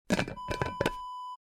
Glass Jar Lid Close Wav Sound Effect #2
Description: The sound of closing a plastic lid of a glass jar
Properties: 48.000 kHz 16-bit Stereo
A beep sound is embedded in the audio preview file but it is not present in the high resolution downloadable wav file.
Keywords: glass, jar, container, lid, plastic, close, closing
glass-jar-lid-close-preview-2.mp3